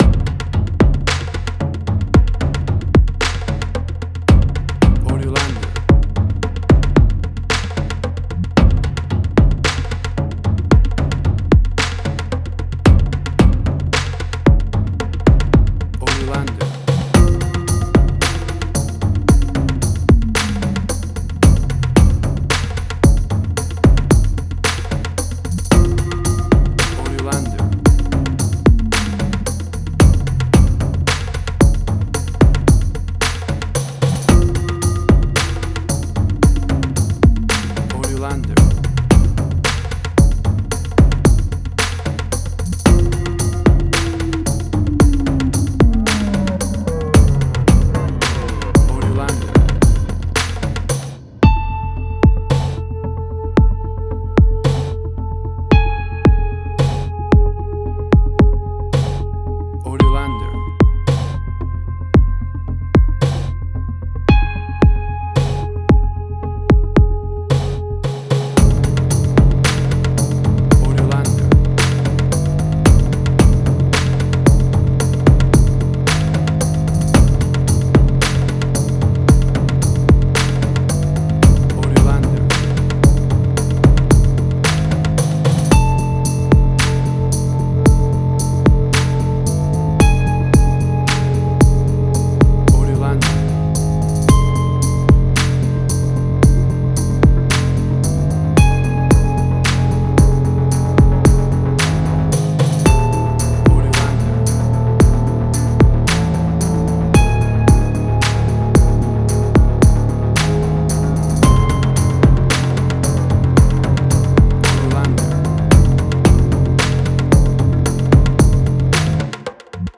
Suspense, Drama, Quirky, Emotional.
Tempo (BPM): 112